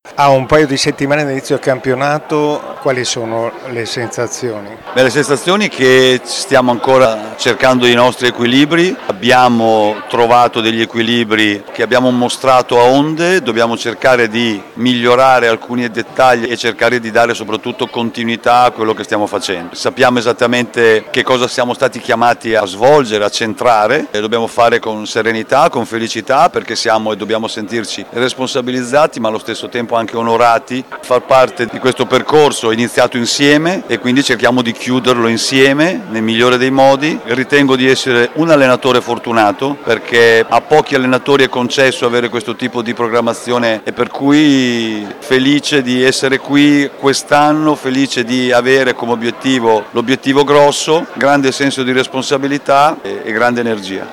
Alla Maison Vicentini è andata in scena la serata gialloblù dedicata alla Tezenis Verona che, a due settimane dall’inizio del campionato, ha voluto presentarsi ad autorità, sponsor e alla città con la presenza di tutta la Società, staff e atleti.